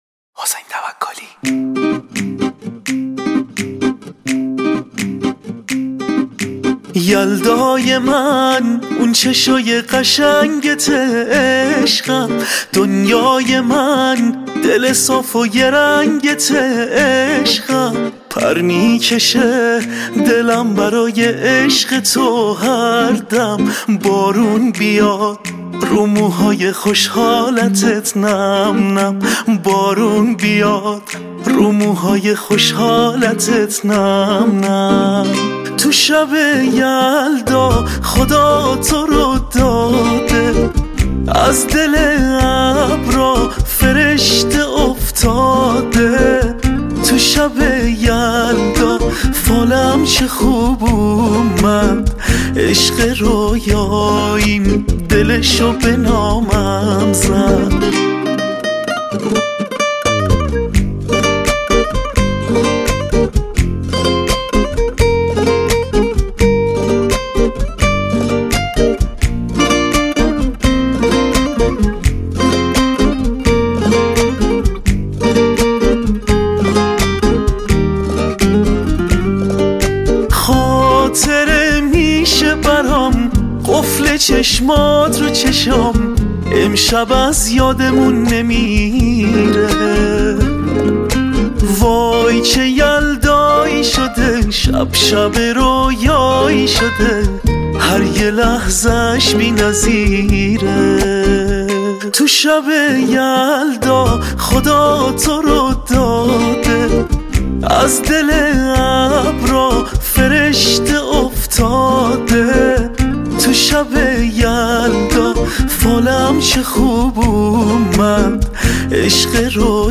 گیتار
تک اهنگ ایرانی